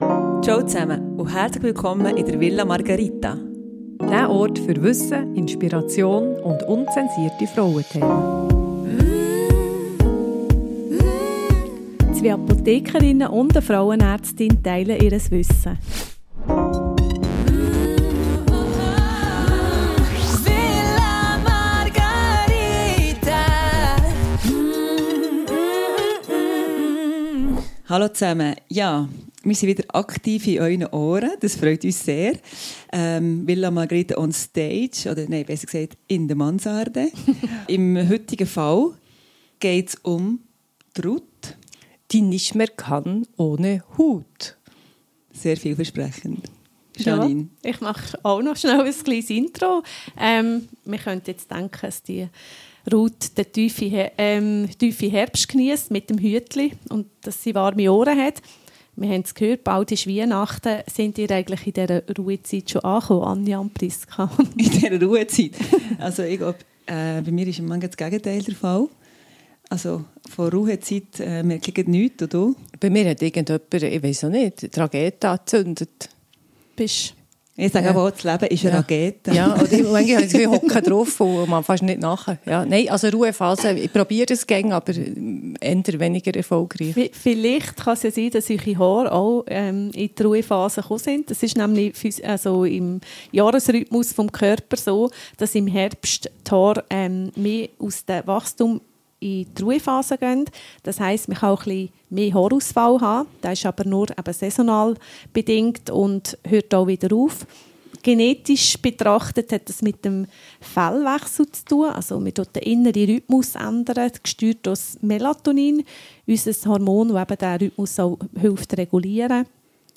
In dieser haarigen Podcast-Episode aus der Villa Margarita sprechen zwei Apothekerinnen und eine Frauenärztin über Hormone und Haare, Kollagen & Supplemente und evidenzbasierte Therapien.